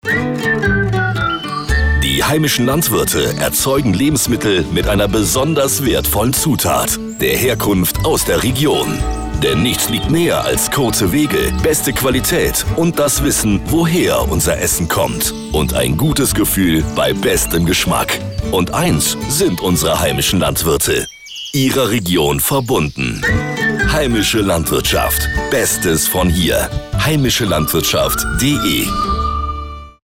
Radiospots zum Herunterladen